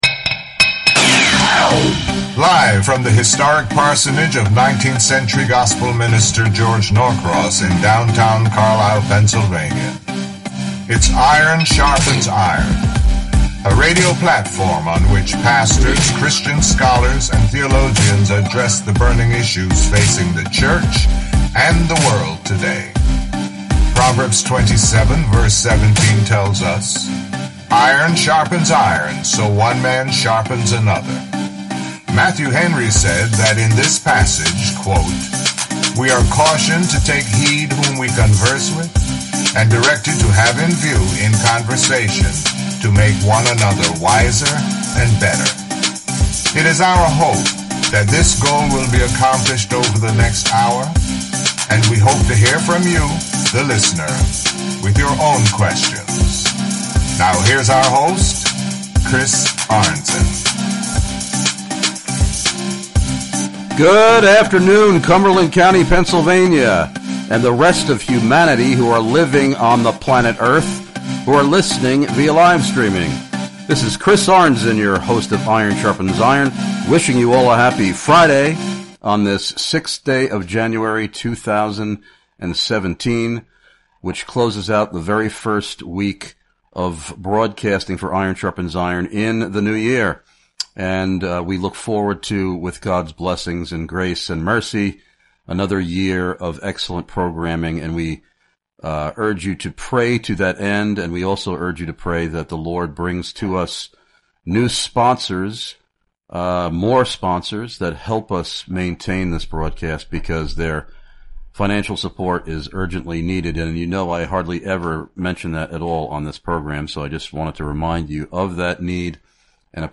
Show